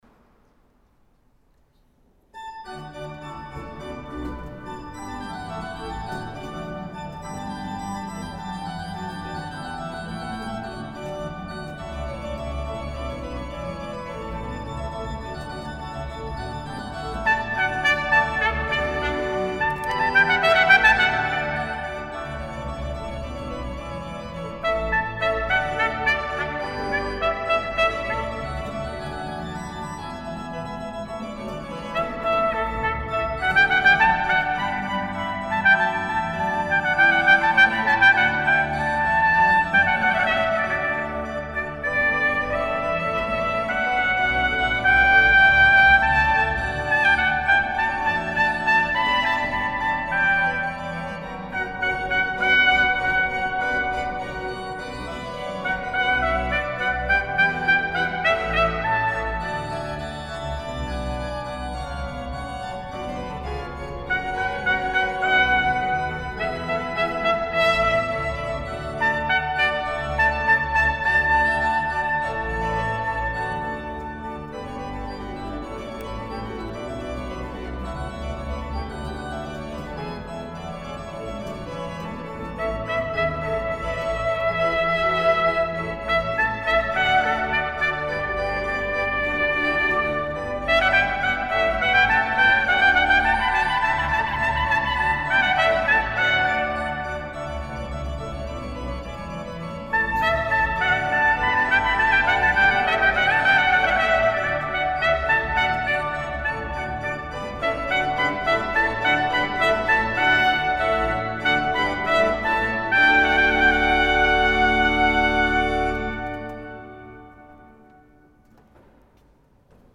Audio concert biennale 2023
Le samedi 30 septembre 2023, en l'église saint Didier de voreppe. Ecoutez l'orgue cavaillé-Coll construit en 1859 et après un parcours mouvementé s'est retrouvé à Voreppe.